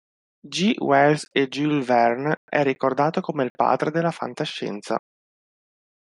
Read more The seventh letter of the Italian alphabet, called gi and written in the Latin script. Frequency C1 Pronounced as (IPA) /ˈd͡ʒi/ Show popularity over time Write this word J G T Jan.